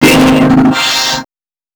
037 male.wav